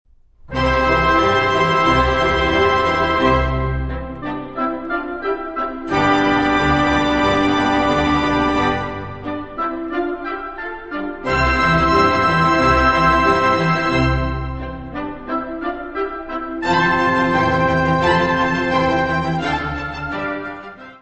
Notes:  Gravado no Concert Hall, New Broadcasting House, Manchester, de 26 a 27 de Outubro, 1993; Disponível na Biblioteca Municipal Orlando Ribeiro - Serviço de Fonoteca
Music Category/Genre:  Classical Music
Allegro assai.